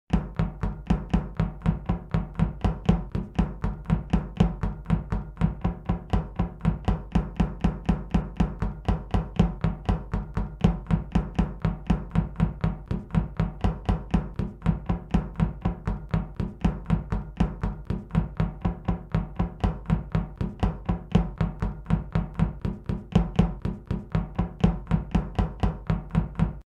RunningOnMetal.mp3